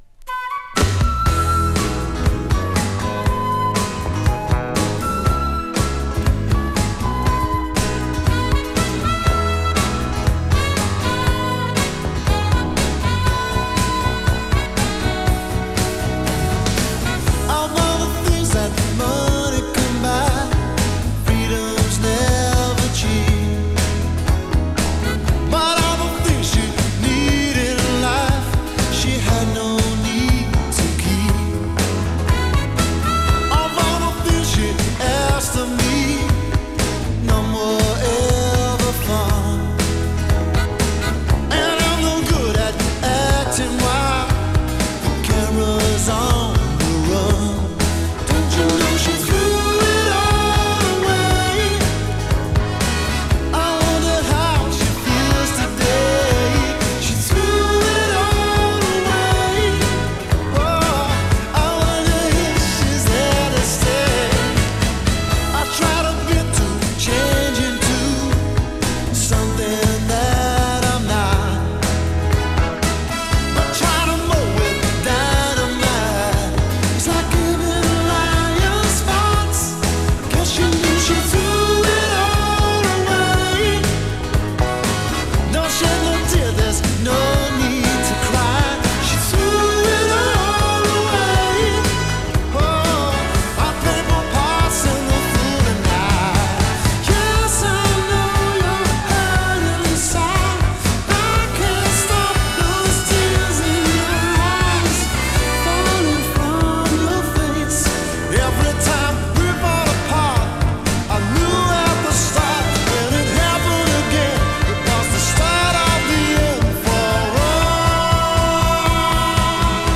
前半はピアノを中心としたアコースティック・サウンド、後半はブラコン系といった変則的な構成。